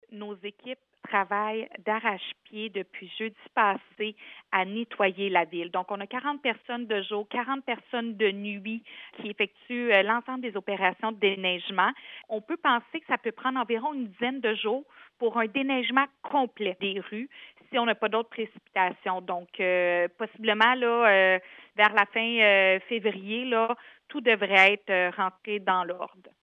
Julie Bourdon, mairesse de Granby.